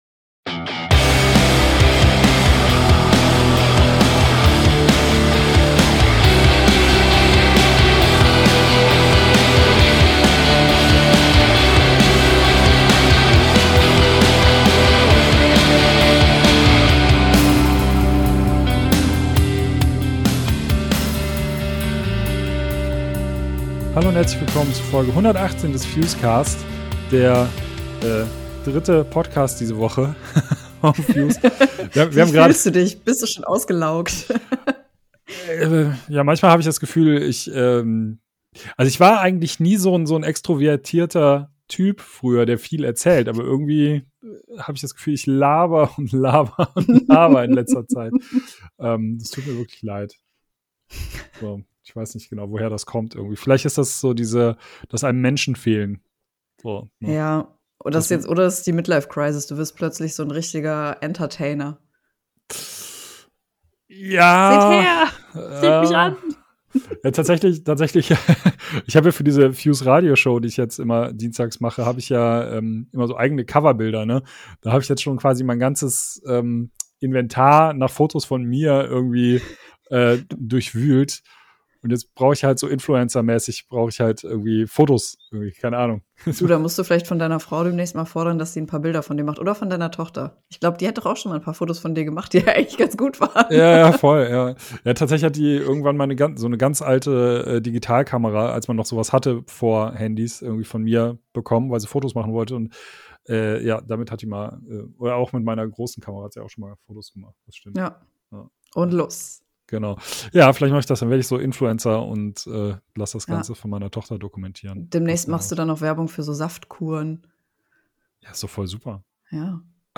Außerdem im Interview